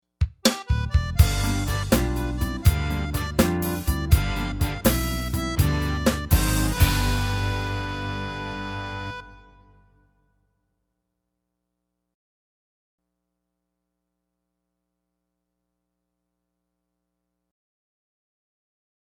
Voicing: Harmonica